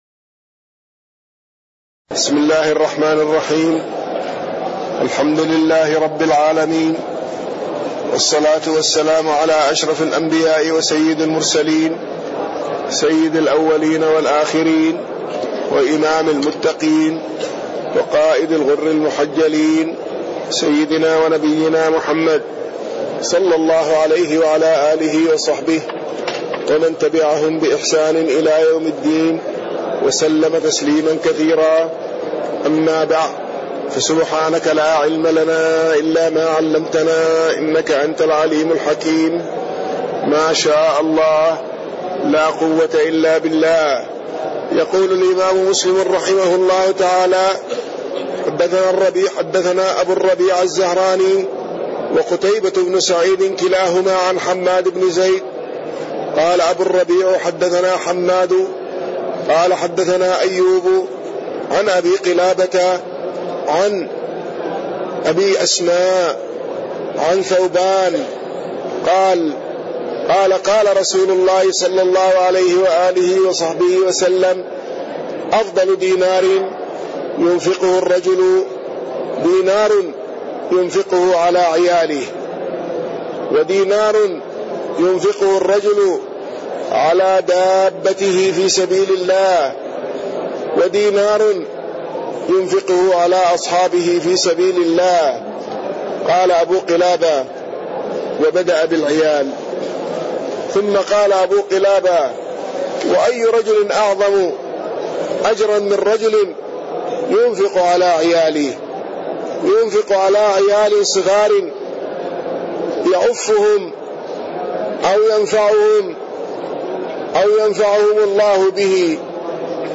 تاريخ النشر ٨ جمادى الآخرة ١٤٣٢ هـ المكان: المسجد النبوي الشيخ